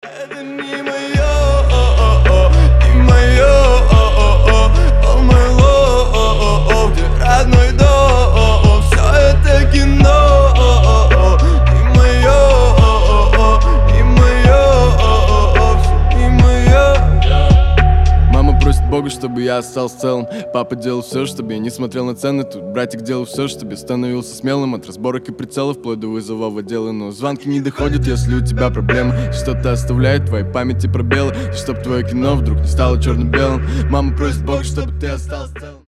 • Качество: 320, Stereo
лирика
душевные